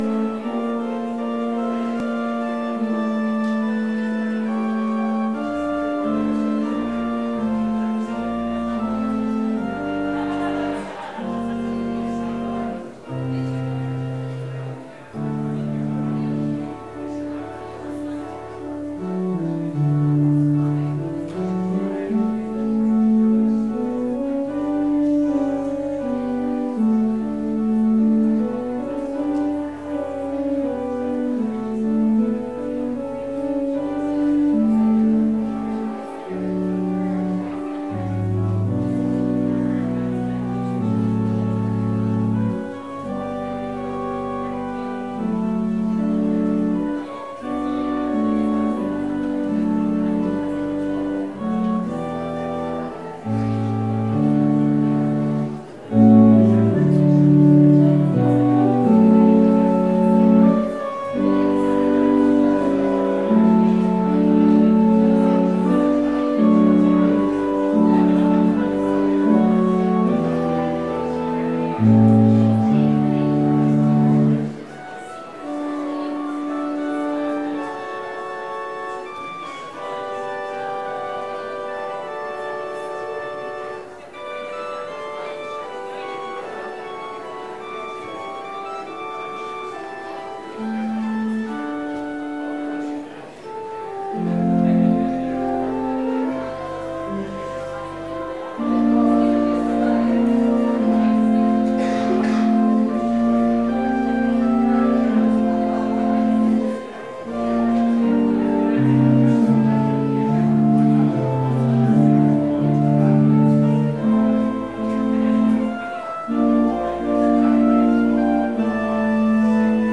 Bible Text: Luke 1:46-55 | Preacher